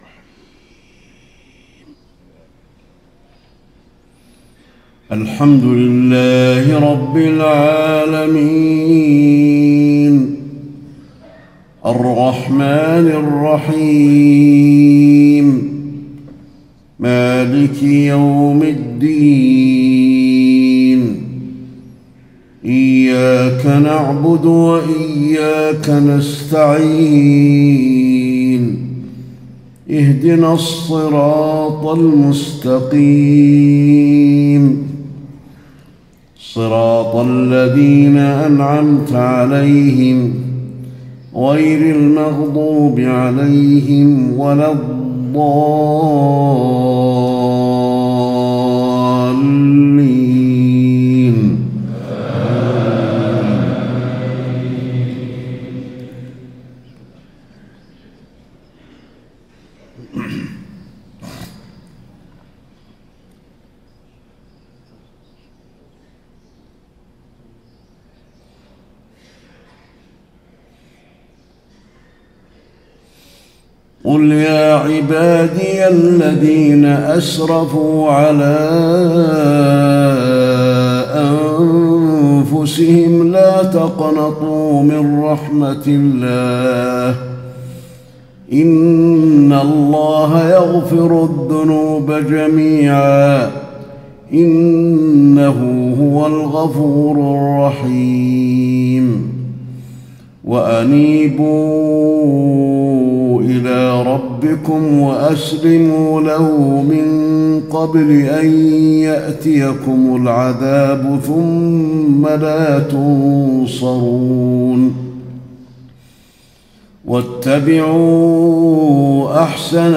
صلاة الفجر ١٣ ربيع الأول ١٤٣٥هـ خواتيم سورة الزمر53-75 > 1435 🕌 > الفروض - تلاوات الحرمين